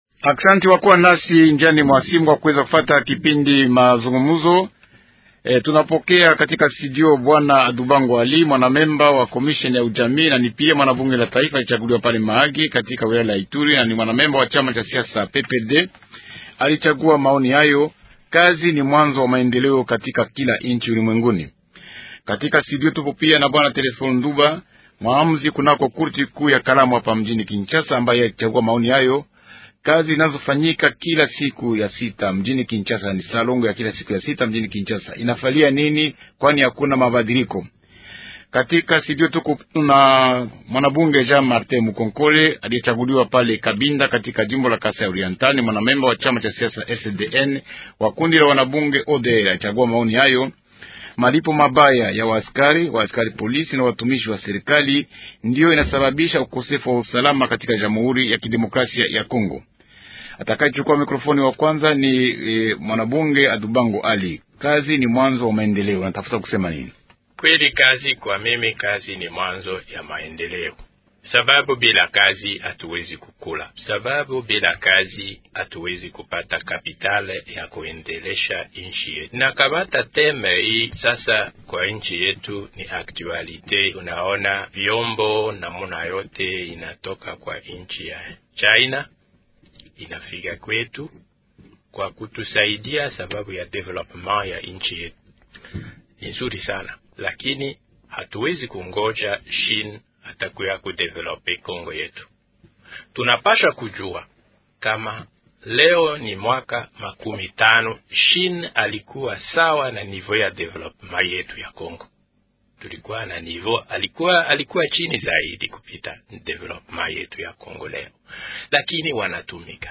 Ce sont là les thèmes du débat de ce soir.